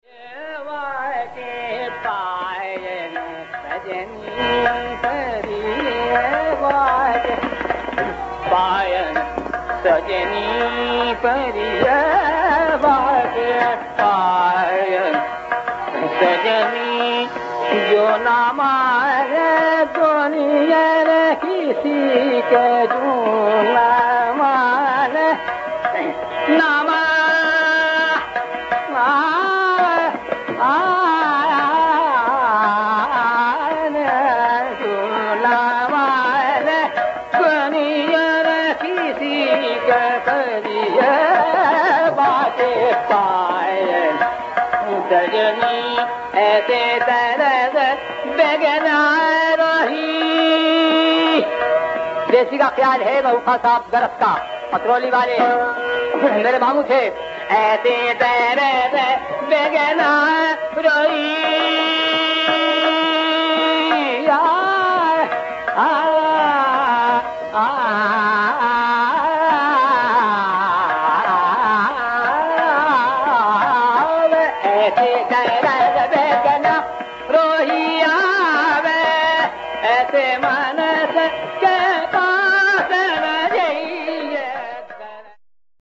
In the concluding leg, we turn to the d-flavoured Desi where, as a consequence, the Asavari anga is more explicit.
The Rampur doyen
Mushtaq Hussain Khan offers a cheez of ‘Daraspiya’ (Mehboob Khan of Atrauli).